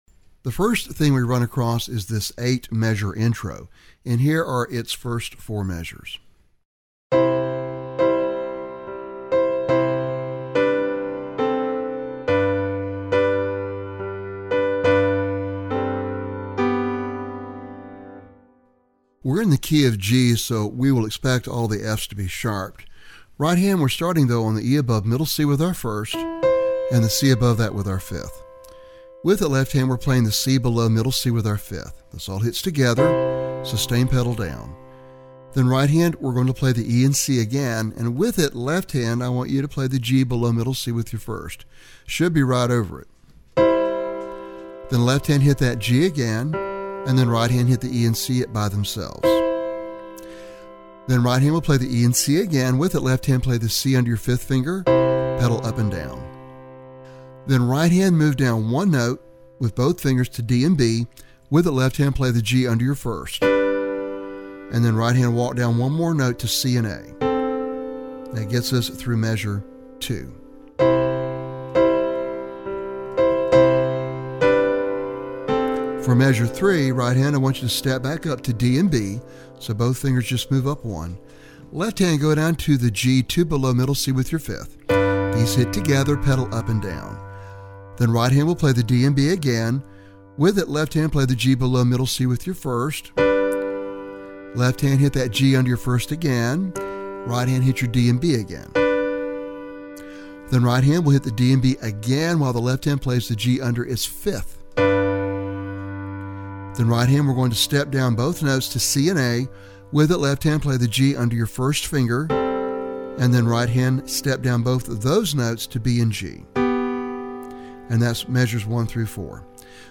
Lesson Sample
gospel-style piano solo